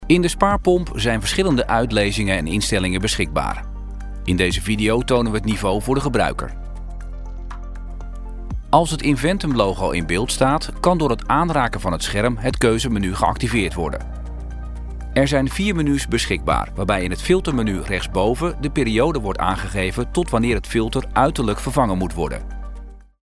Natuurlijk, Opvallend, Toegankelijk, Vertrouwd, Vriendelijk
E-learning